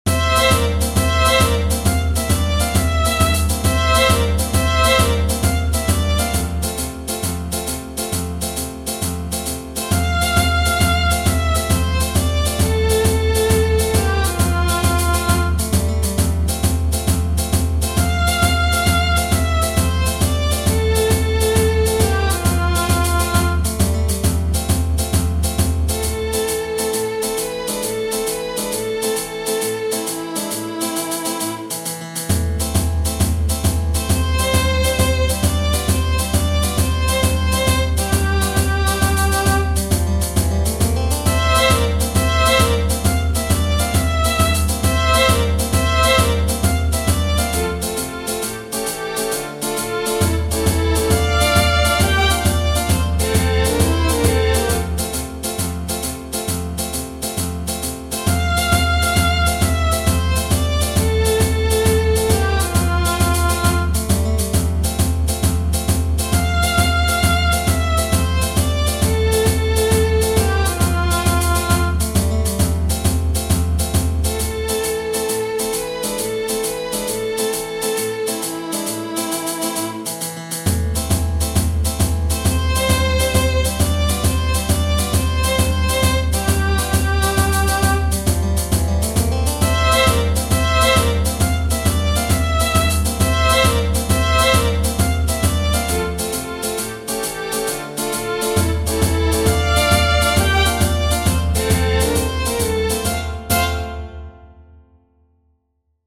Denza, L. Genere: Napoletane Testo di G. Turco, musica di L. Denza.